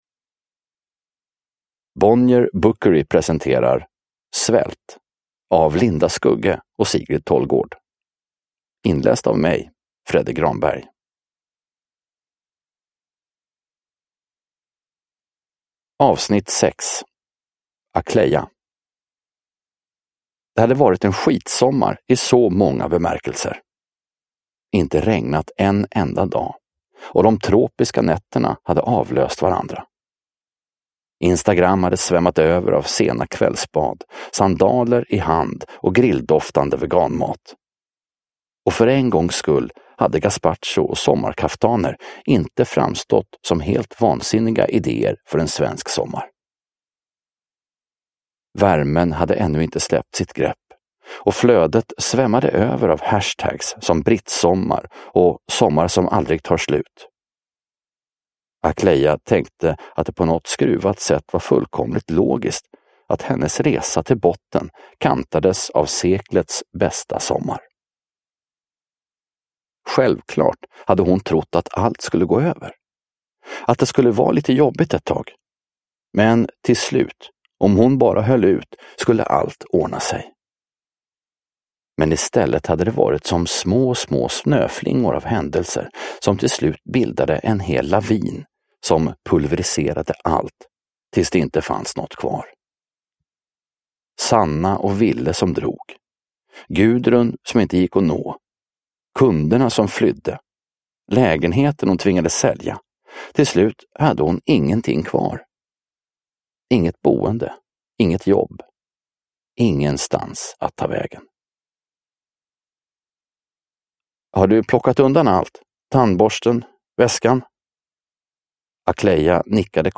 Svält. S1E6 – Ljudbok – Laddas ner